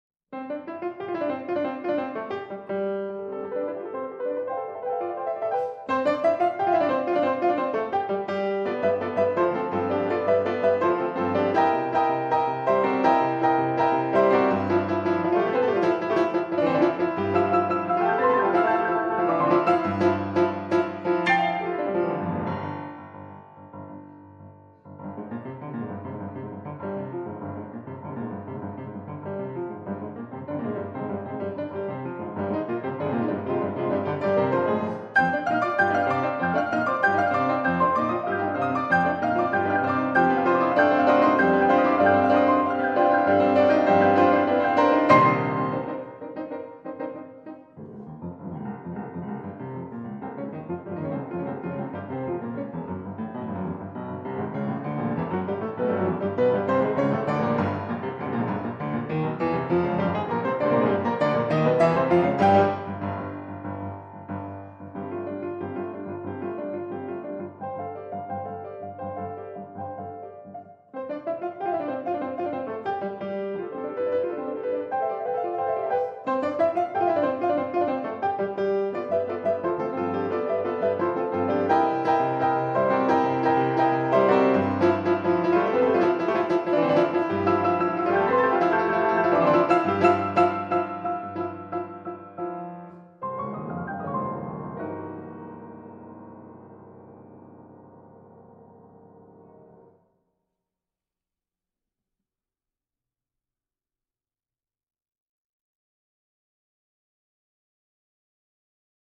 Sonate piano à quatre mains